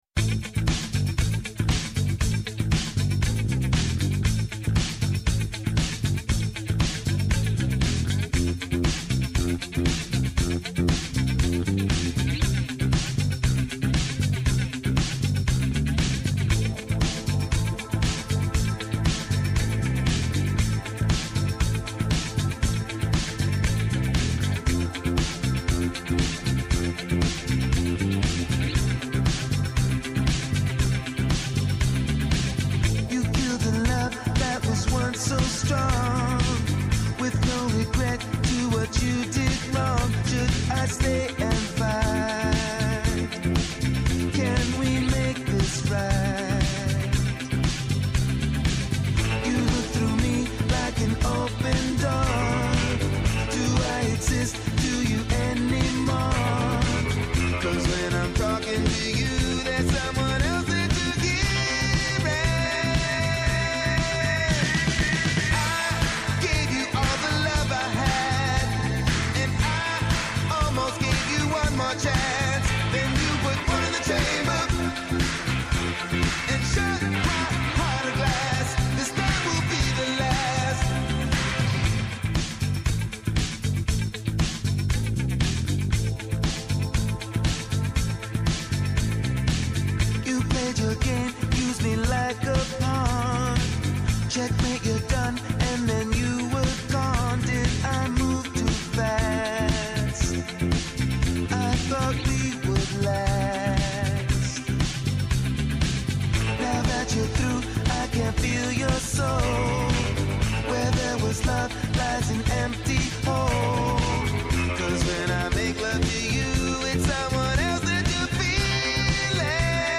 Σήμερα ένας εικαστικός περίπατος σε τρέχουσες και επόμενες εκθέσεις με καλεσμένους τους :
‘Ενα ραδιοφωνικό “βήμα” σε δημιουργούς που τολμούν, αναδεικνύουν την δουλειά τους, προτείνουν και αποτρέπουν.